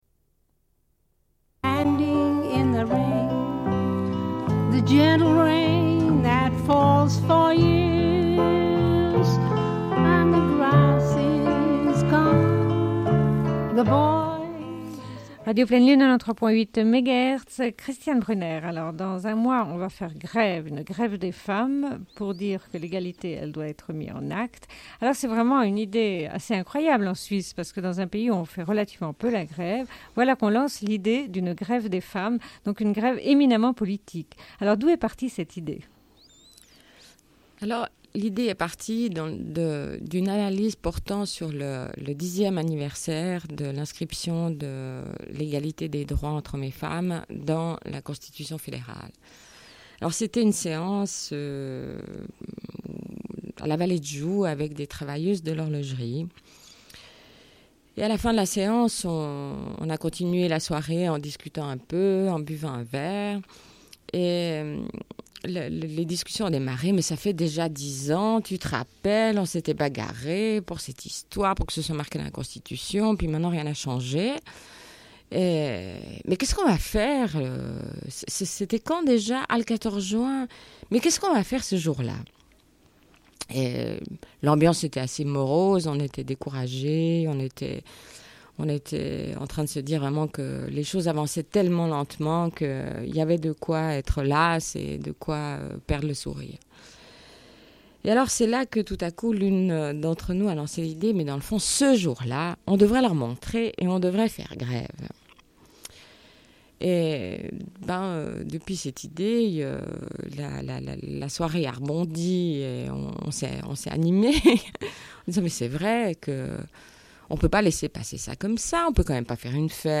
Une cassette audio, face B29:34